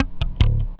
10BASS02  -L.wav